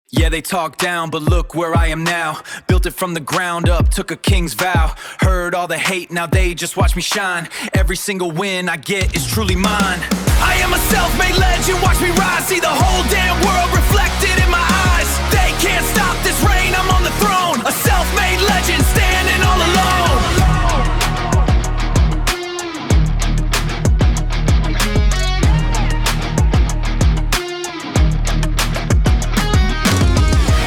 Category:Rap